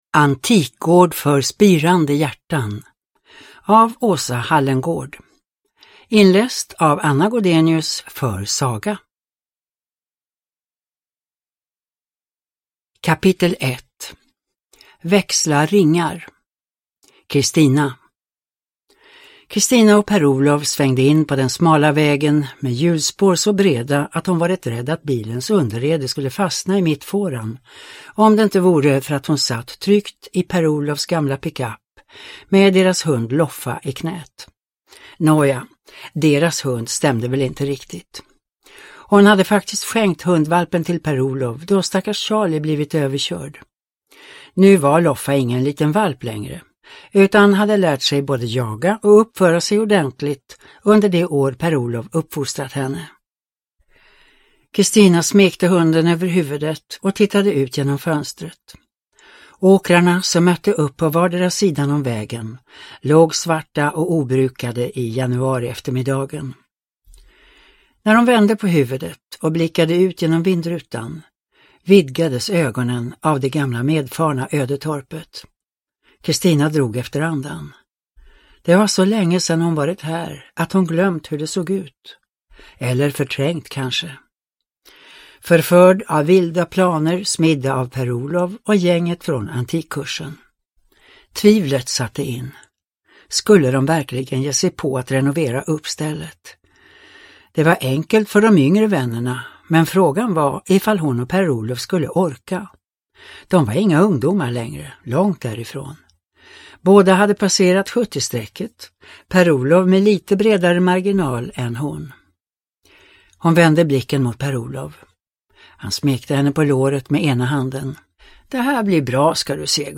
Antikgård för spirande hjärtan / Ljudbok